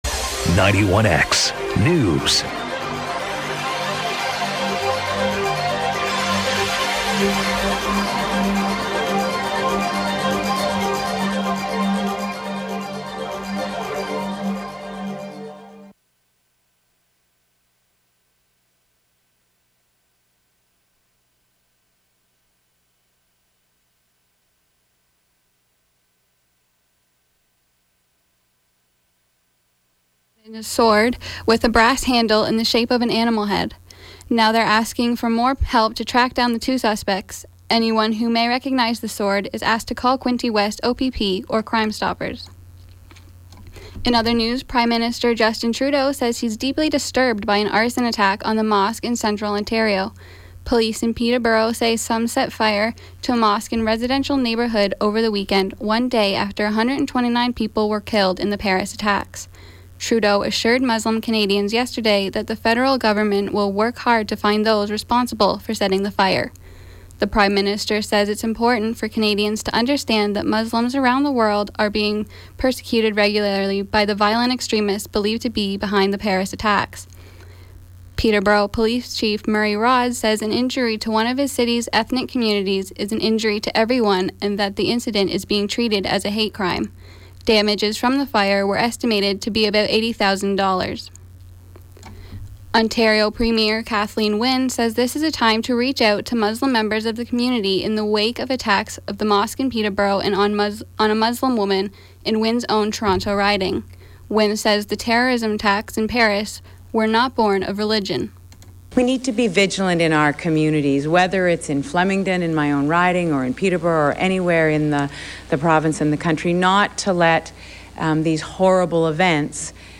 91X newscast – Tuesday, Nov. 17, 2015 – 12 p.m.